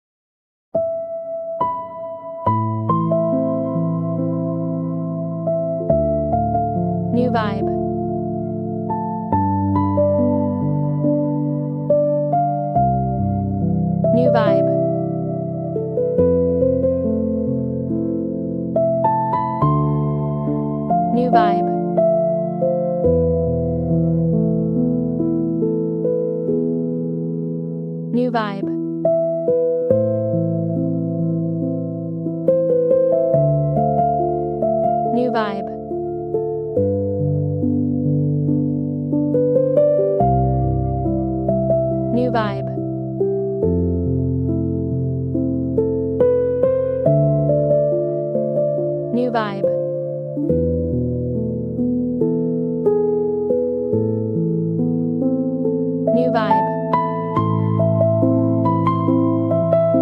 Genre: Easy Listening, piano